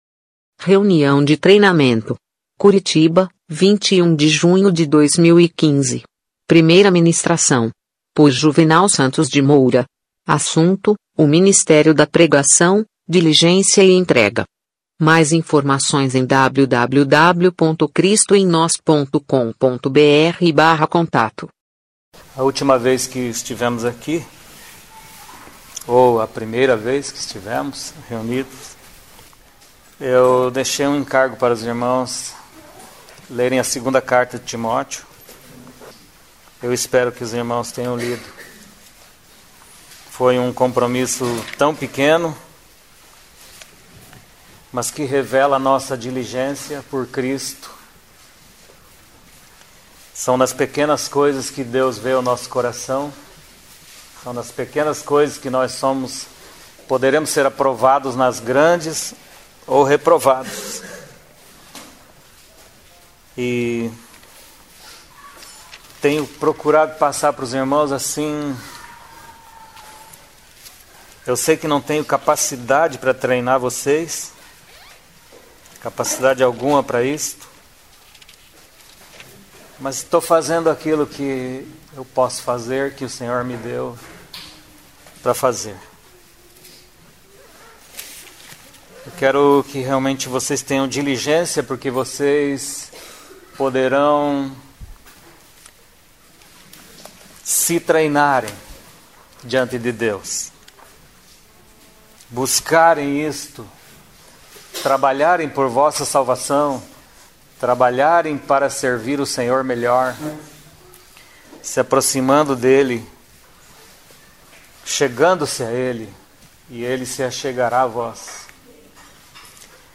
em uma reunião para o ensino sobre o ministério da pregação